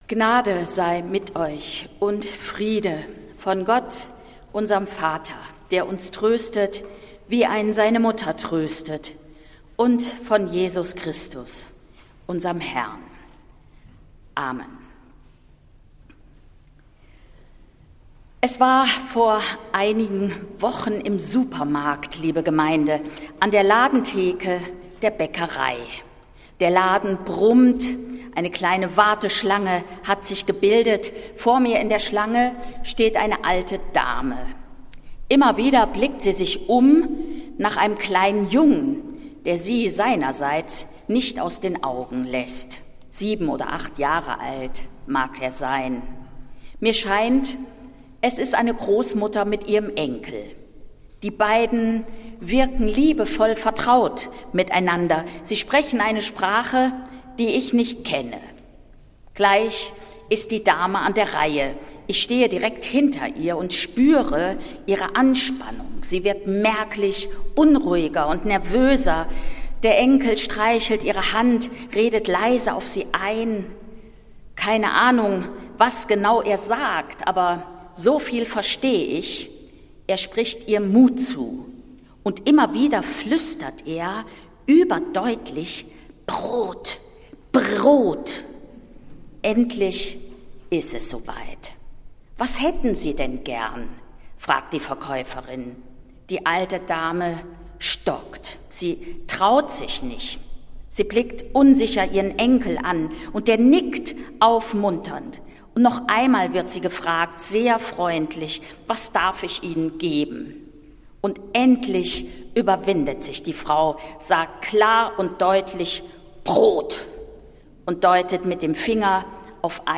Predigt des Gottesdienstes aus der Zionskirche am Sonntag, den 30. März 2025
Die Predigt an diesem Sonntag hielt Präses a. D., Dr. h. c. Annette Kurschus